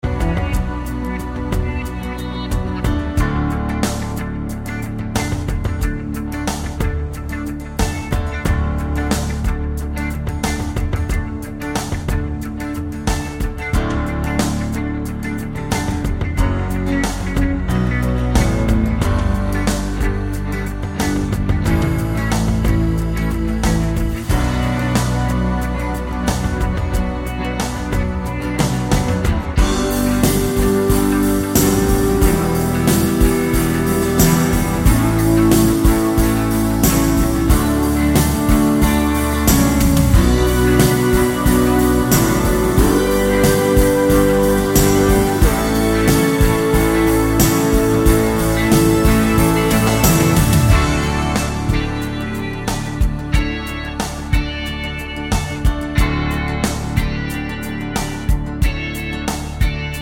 No Harmony Pop (1980s) 3:37 Buy £1.50